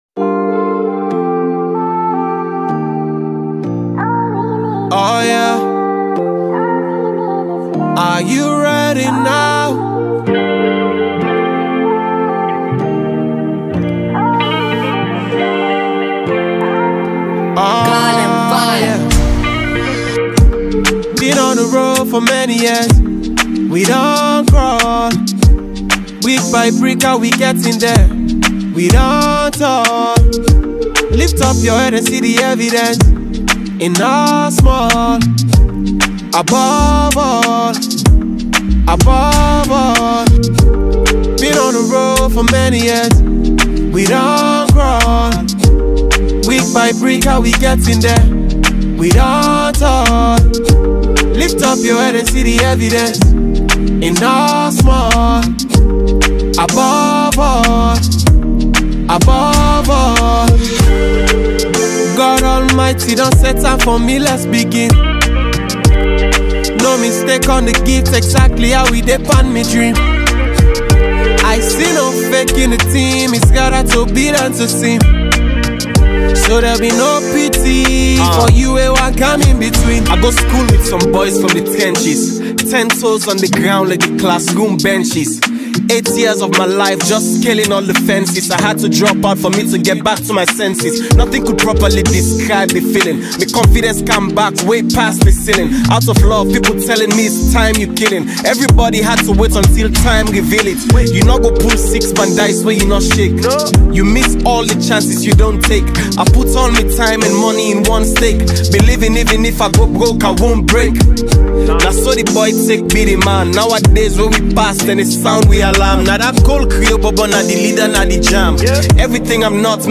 Multiple award-winning rapper